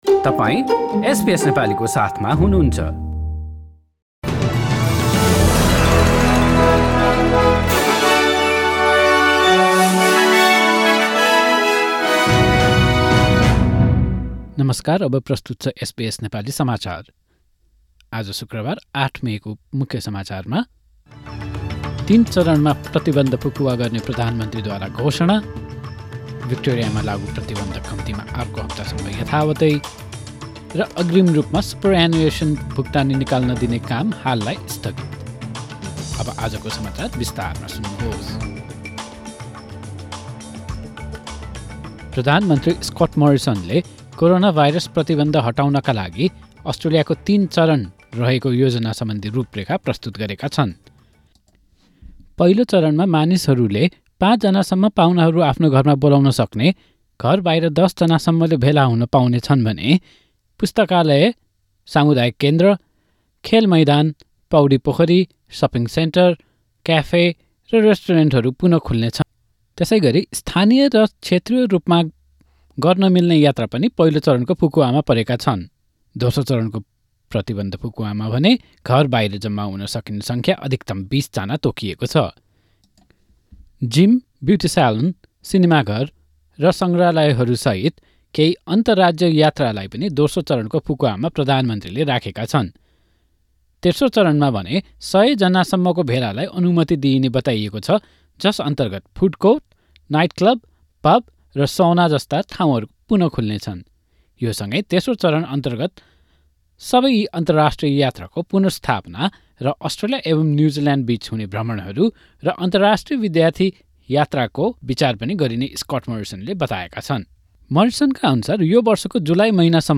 Listen to the latest news headlines in Australia from SBS Nepali radio